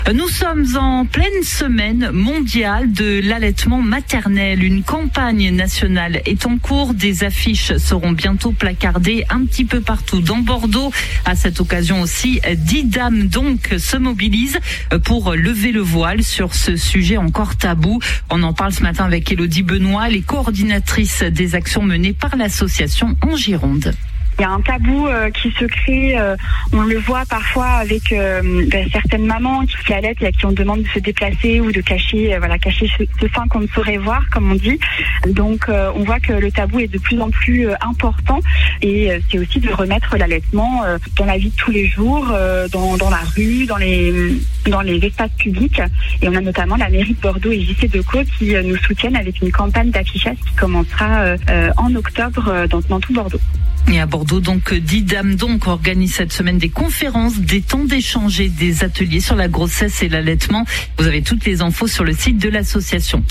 Interview DisDameDonc SMAM 2025